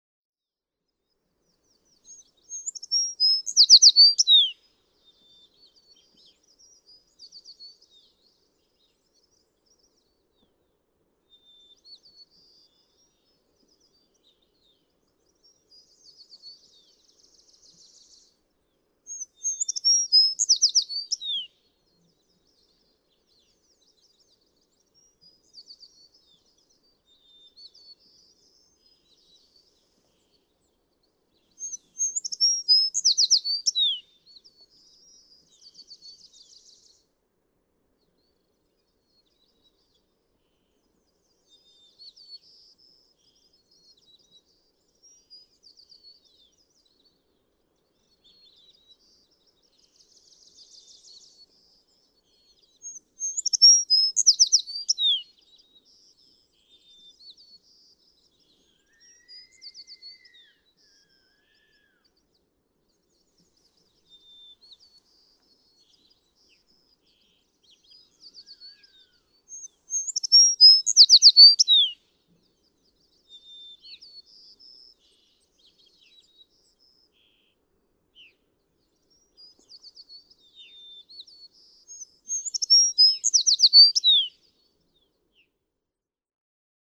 American tree sparrow
♫276, ♫277, ♫278—longer recordings from those three individuals
276_American_Tree_Sparrow.mp3